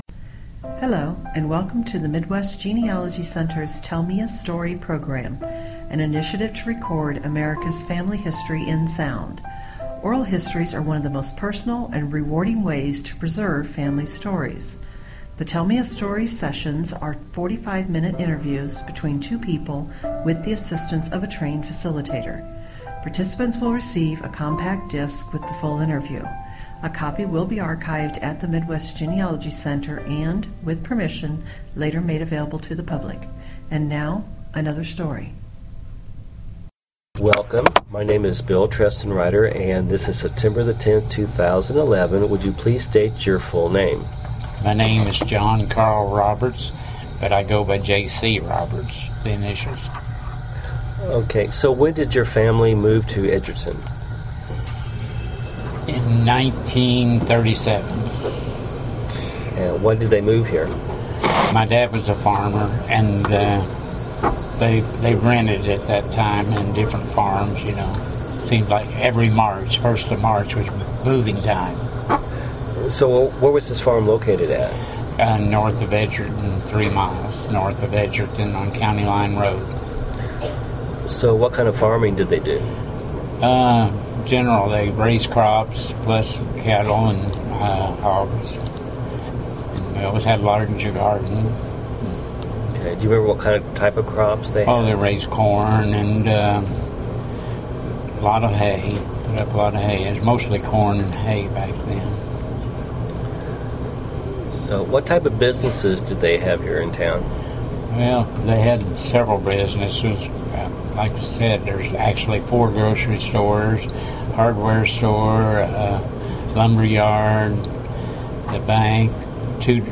Oral History
Edgerton, Missouri Pioneer Days 2011